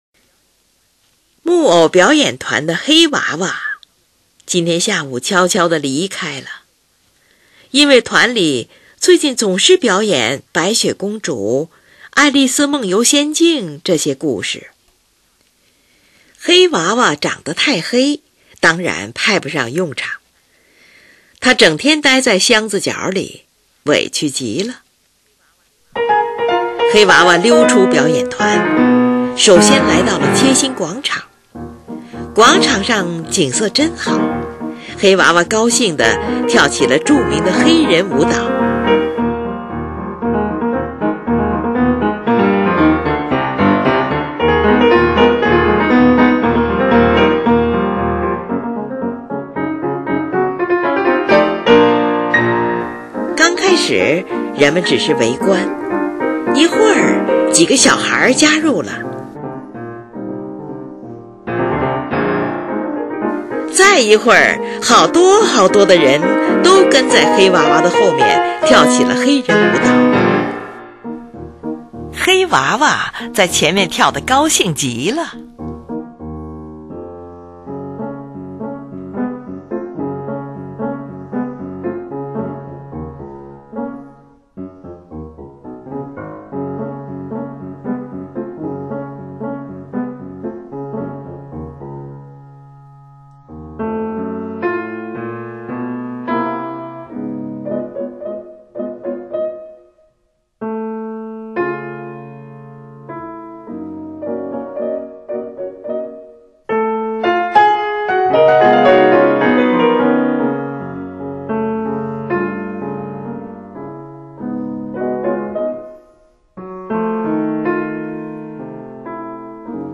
节奏感强是这首乐曲的特点。
曲式结构是A-B-A，B段的静态与A段形成强烈的对比，但是不时出现的快速动机又不断地提醒我们别忘了这首乐曲的基本情绪。
在一串快速的下行音型后，一个重音结束了乐曲。